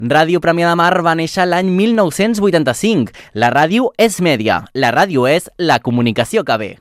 Enregistrament fet amb motiu del Dia mundial de la ràdio 2022.
FM